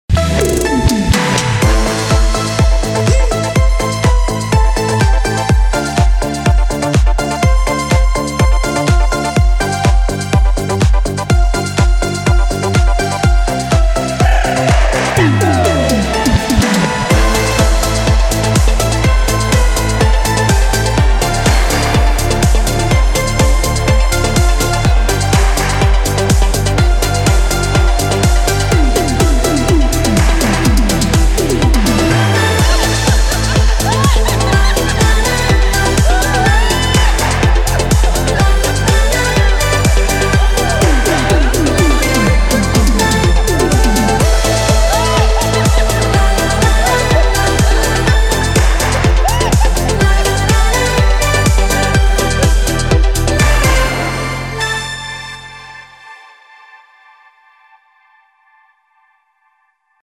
• Качество: 320, Stereo
синти-поп
Веселая, зажигательная музыка на звонок